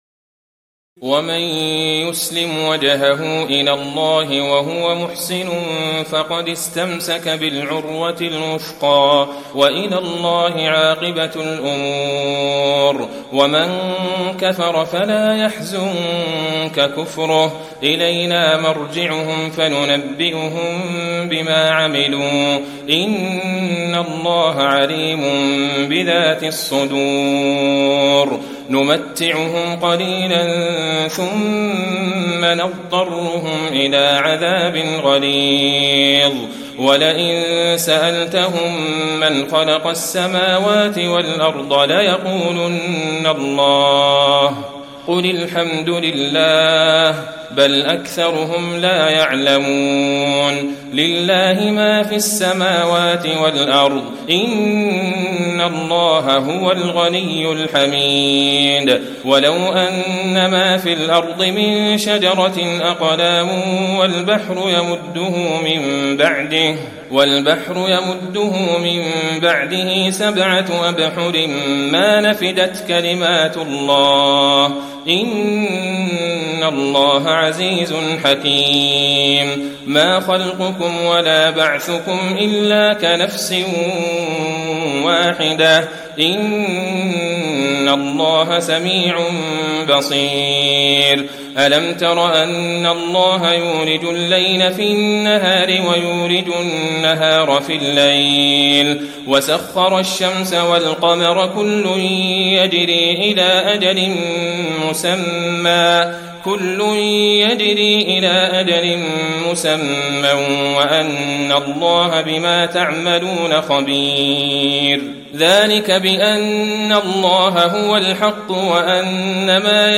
تراويح الليلة العشرون رمضان 1435هـ من سور لقمان (22-34) و السجدة و الأحزاب (1-34) Taraweeh 20 st night Ramadan 1435H from Surah Luqman and As-Sajda and Al-Ahzaab > تراويح الحرم النبوي عام 1435 🕌 > التراويح - تلاوات الحرمين